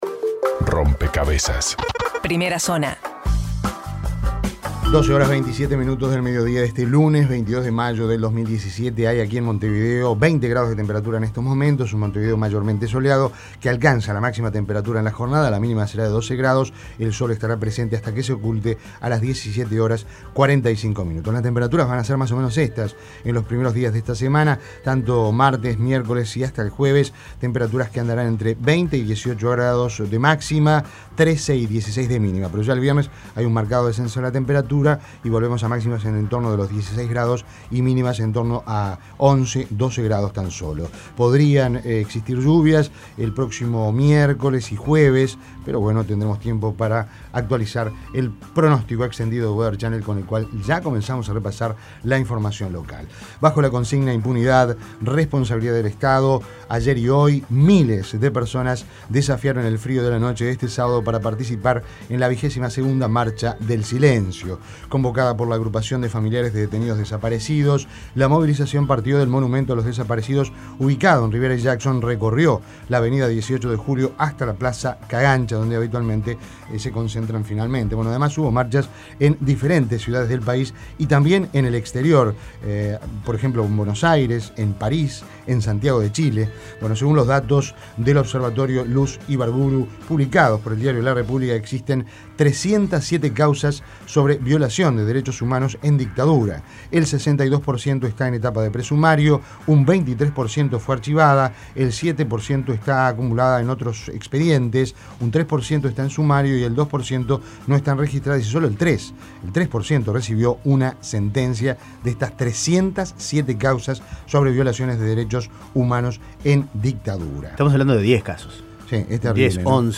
Las principales noticias nacionales, resumidos en la Primera Zona Nacional de Rompkbzas.